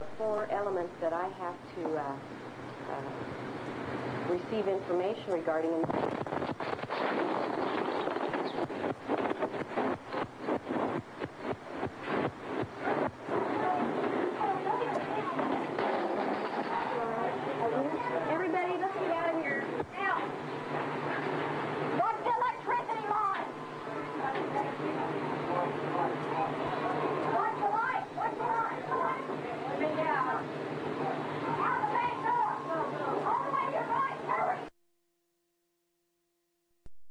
as the bomb exploded.